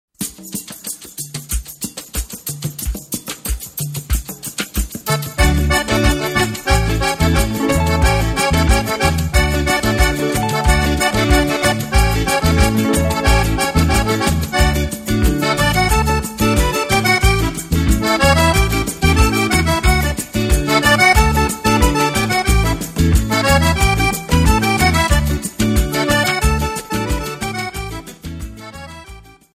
Bayon